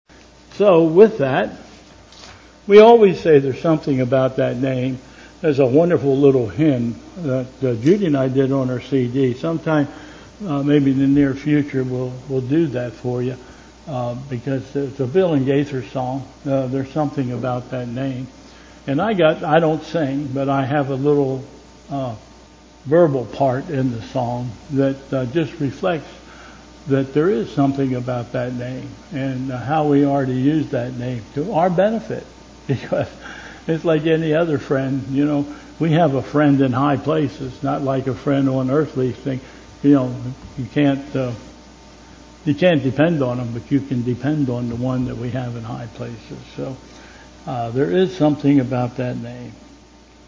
Bethel Church Service